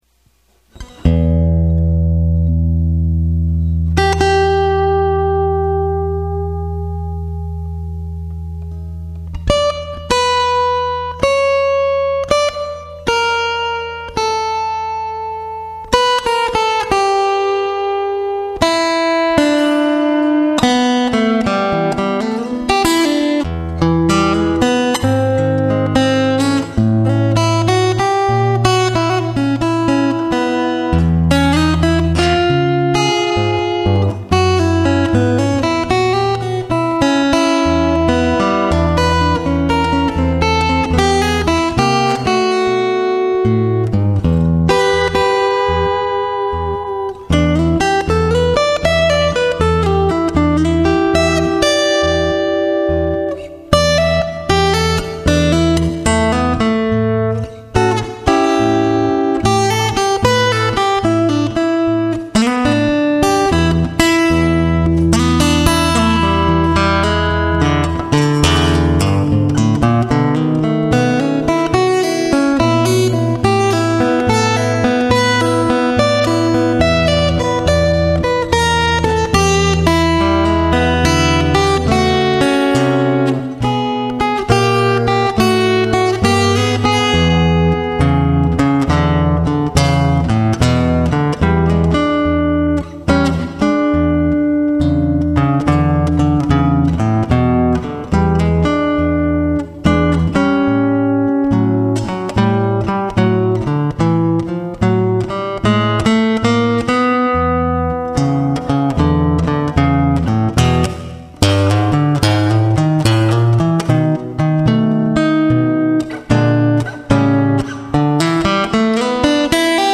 This is my personal ode to solo fingerstyle blues guitar, played on my Takamine acoustic plugged directly into the computer.
The rest of the piece is just more E blues ideas over dead thumb and walking bass.